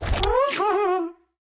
Minecraft Version Minecraft Version snapshot Latest Release | Latest Snapshot snapshot / assets / minecraft / sounds / mob / happy_ghast / goggles_down.ogg Compare With Compare With Latest Release | Latest Snapshot
goggles_down.ogg